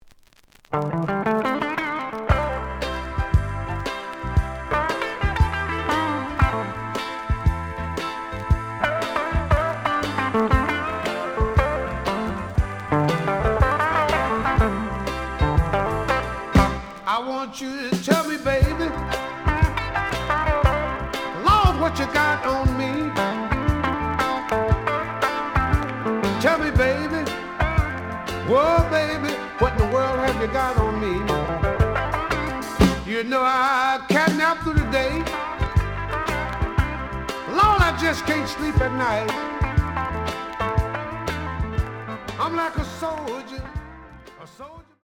The audio sample is recorded from the actual item.
●Genre: Blues
Slight edge warp.